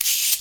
Loops cabassa 2
SONS ET LOOPS CABASSA
Banque sons : Batterie